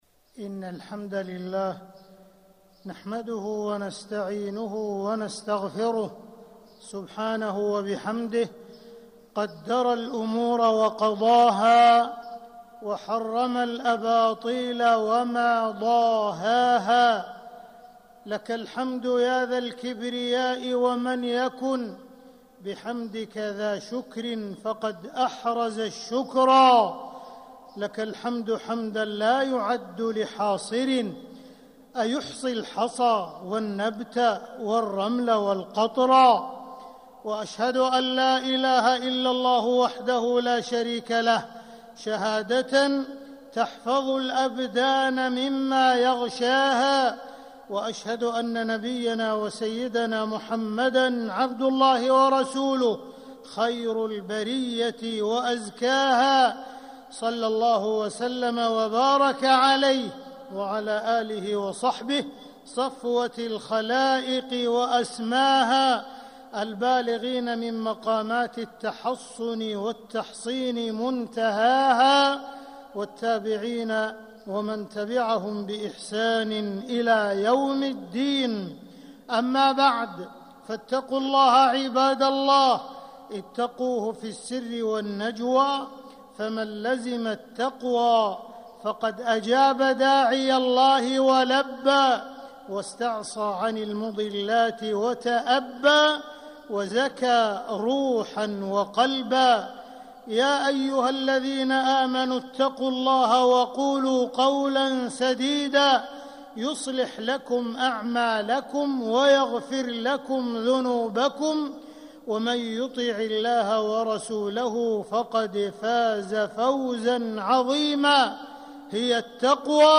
مكة: التحصين والوقاية من السحر والعين وغيرهما - عبد الرحمن بن عبدالعزيز السديس (صوت - جودة عالية